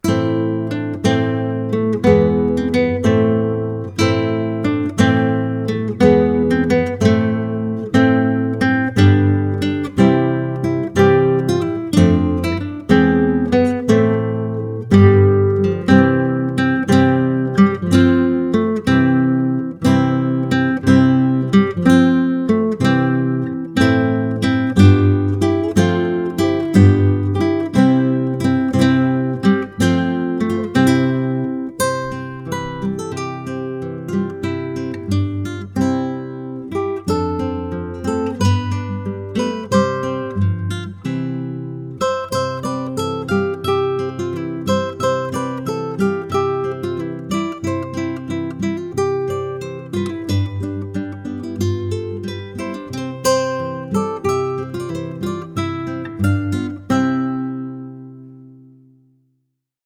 When opened, classical guitar renditions of ‘We Three Kings’ and ‘Joy To The World’ bring added joy to the appreciation of The Original Gifts of Christmas.
Three-Kings-Gifts-music-box-song-FINAL-VERSION-1.mp3